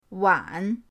wan3.mp3